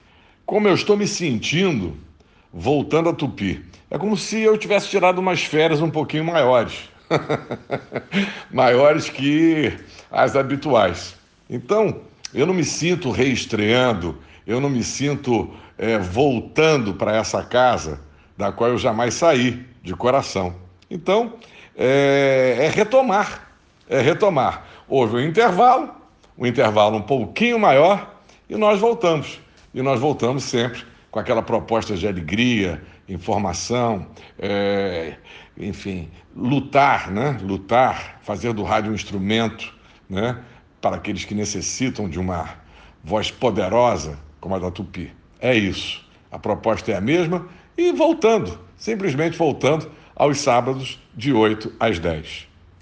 Em conversa com a reportagem da Tupi